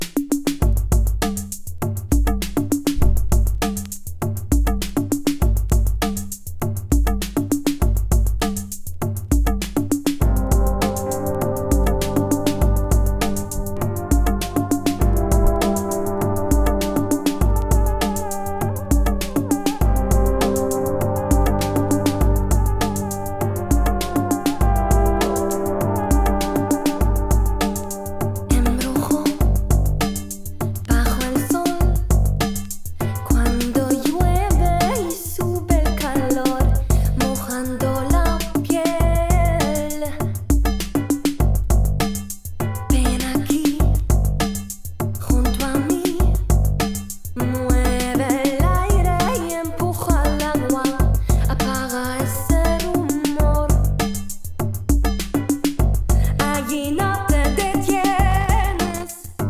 ドリーミー〜耽美〜アンビエントetcが絶妙に混ざりあったサウンド！